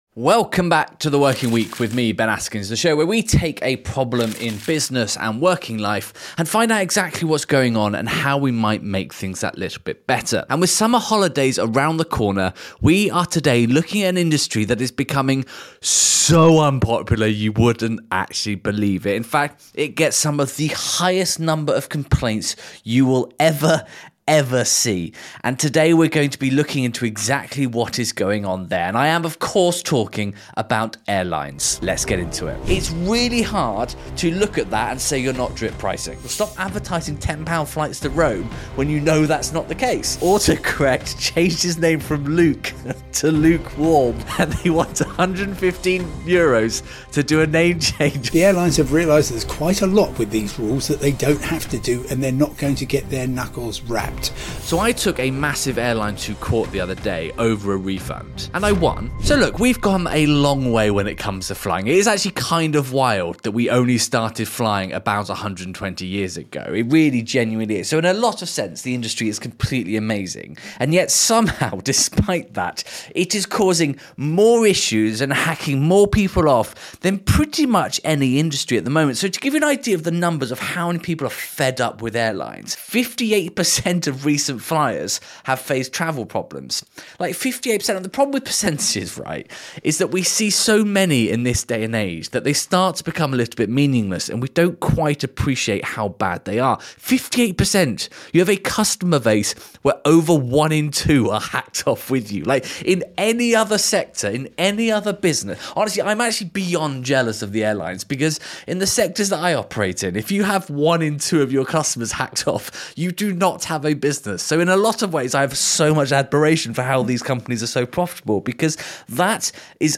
renowned travel journalist and broadcaster, Simon Calder